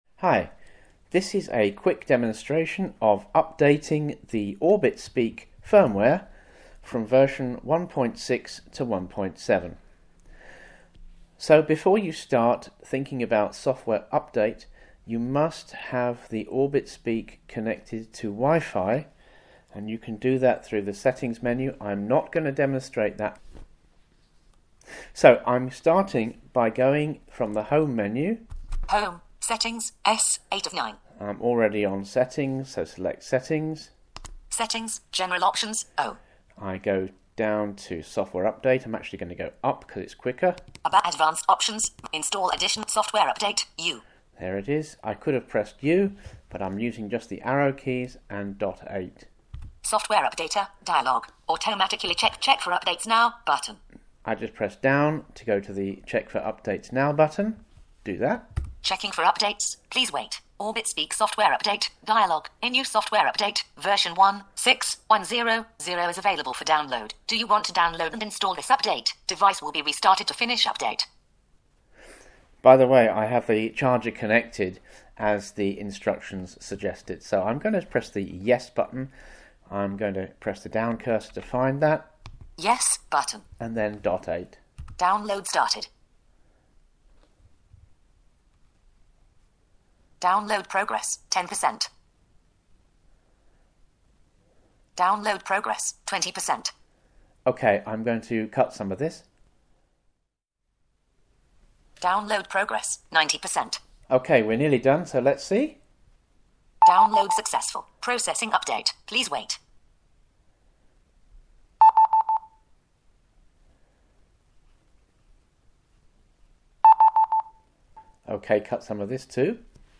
Demo of the update process for the Orbit-Speak Plus
Orbit-Speak Update Demo.mp3